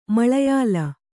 ♪ maḷeyāla